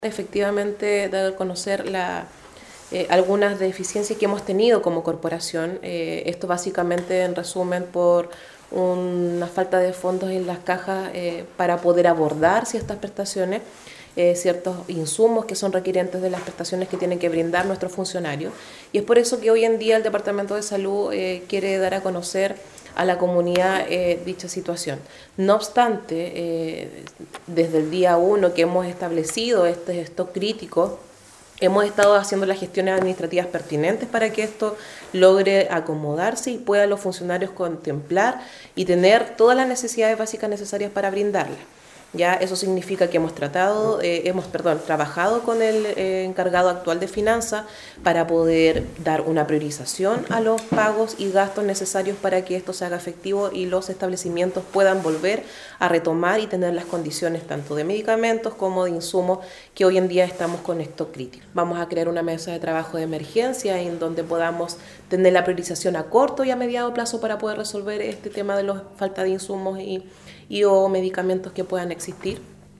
En un punto de prensa, la funcionaria salió a responder el emplazamiento hecho por los trabajadores, expresando que se ha mantenido contacto con ellos, de manera de ponerlos al tanto de la falencia en la disponibilidad de dinero para la adquisición de estos elementos, además de medicamentos y otros.